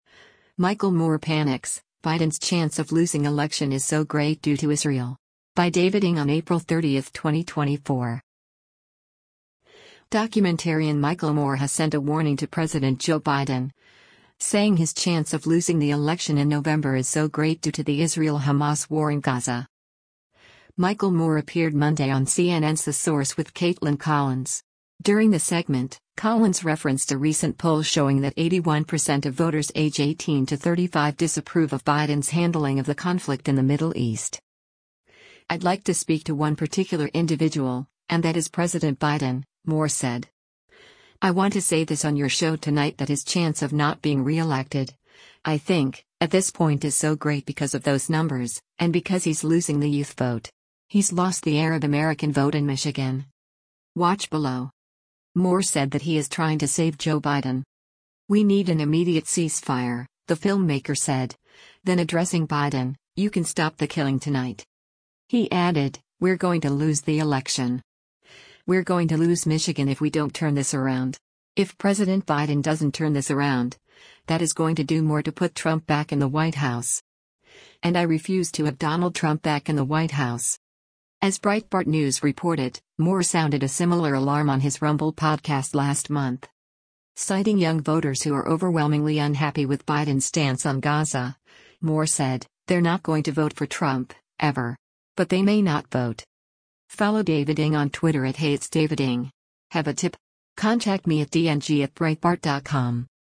Michael Moore appeared Monday on CNN’s The Source with Kaitlan Collins.